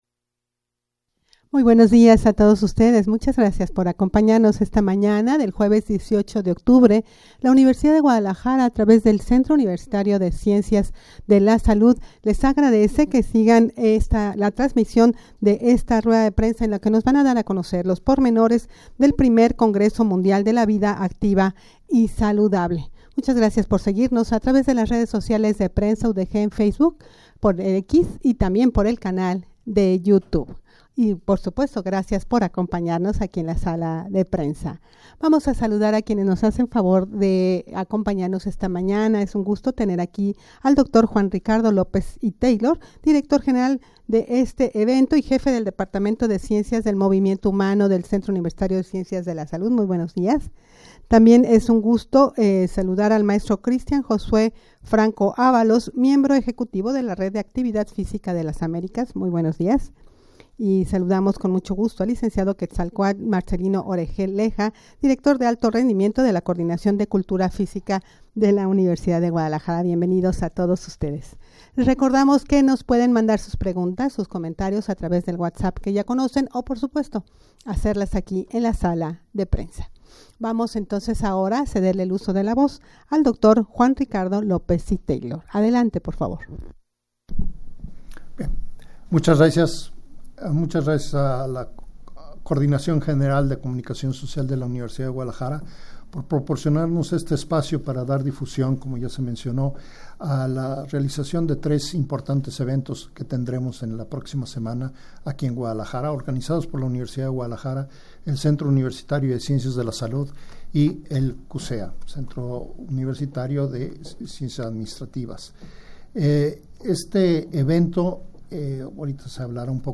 rueda-de-prensa-para-dar-a-conocer-el-i-congreso-mundial-de-la-vida-activa-y-saludable.mp3